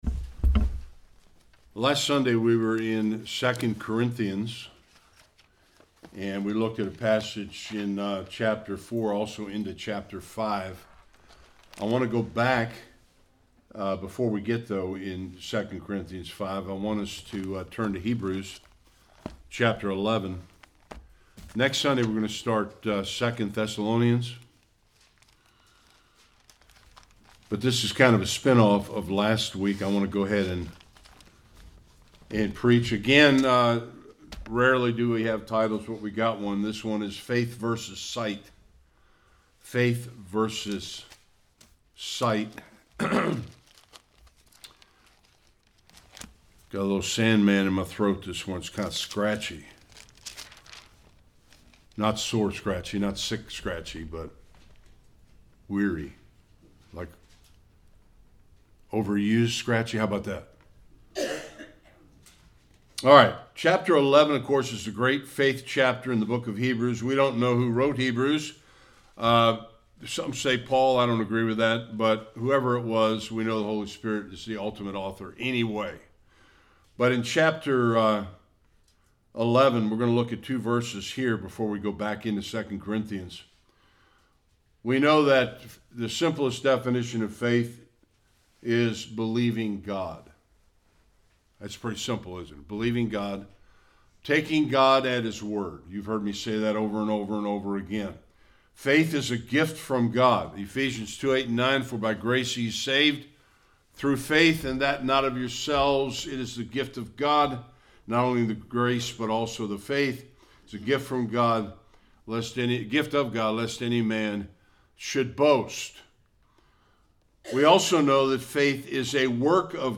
2 Corinthians 5:7 Service Type: Sunday Worship We are saved by grace through faith.